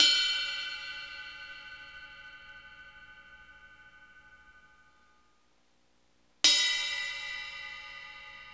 Index of /90_sSampleCDs/Roland L-CDX-01/CYM_Rides 1/CYM_Ride Modules